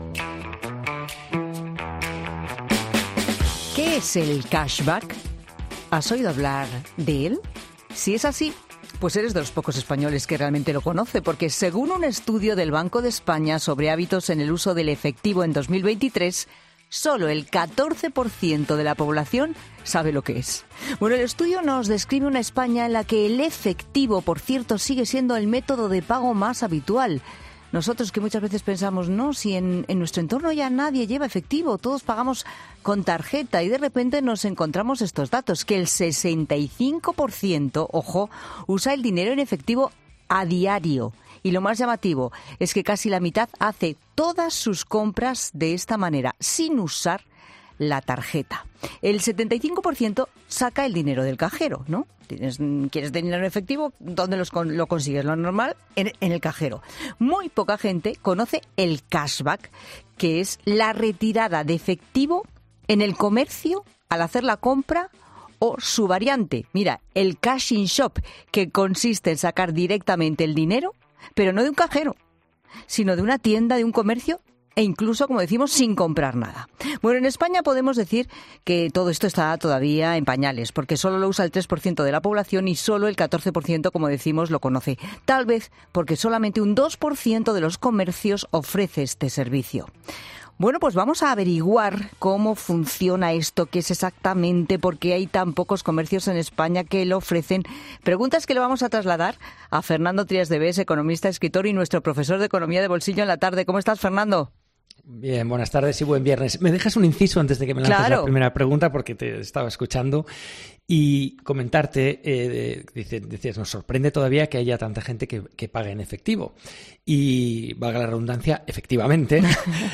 Son preguntas a las que respondemos con Trías de Bes, economista, escritor y profesor de Economía de Bolsillo en 'La Tarde'.